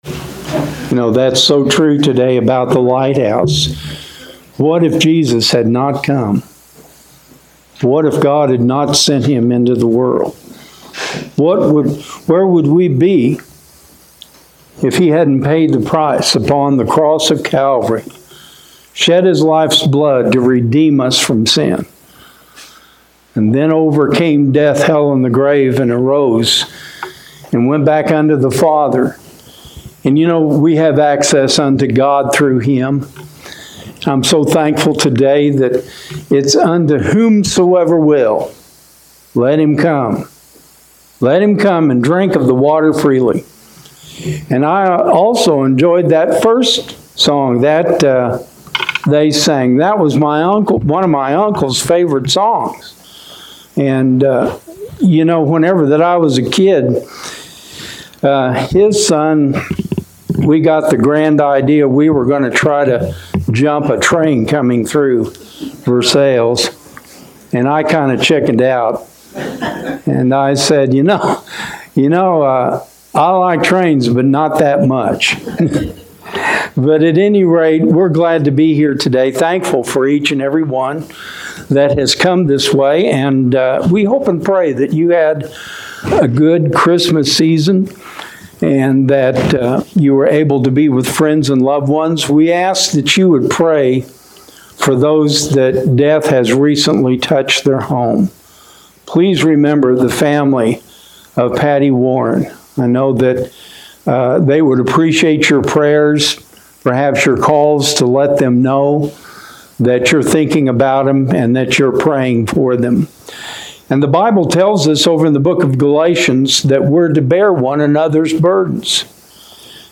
Links to Elkton Baptist Church sermons recorded in 2025 are listed below: